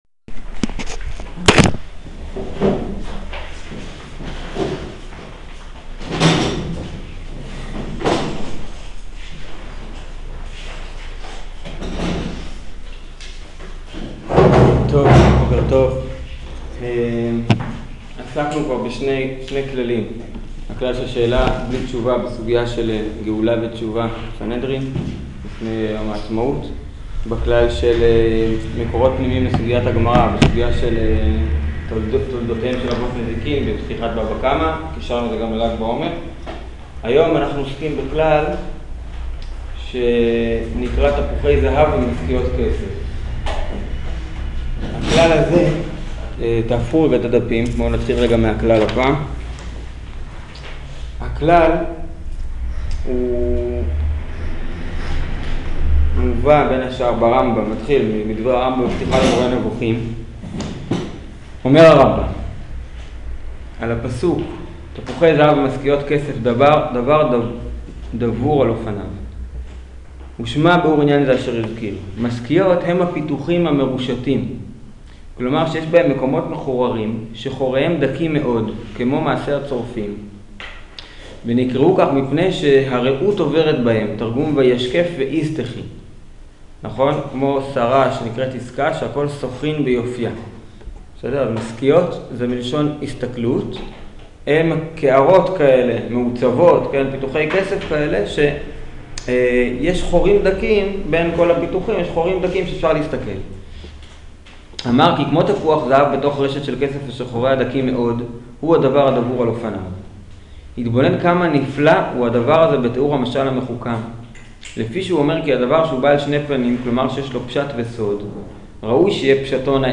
שיעור המגביה מציאה לחבירו